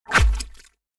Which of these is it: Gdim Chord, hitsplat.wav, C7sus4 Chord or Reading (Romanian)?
hitsplat.wav